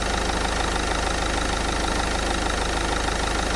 柴油发动机2
描述：在渡船上的固定式柴油发动机。用变焦H1录制的现场录音。
标签： 固定 船舶 渡口 电机 fieldrecording 现场 记录 柴油 fieldrecording 发动机
声道立体声